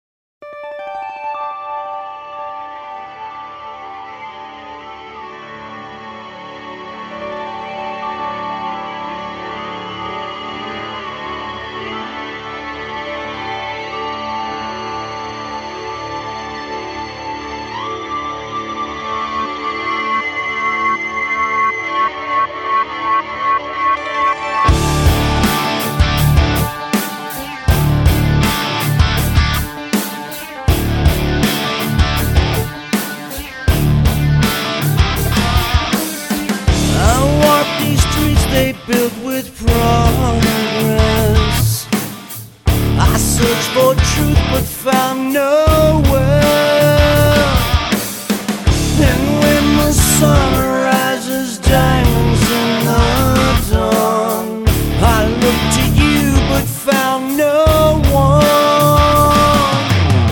Metal
Бескомпромиссный hard&heavy metal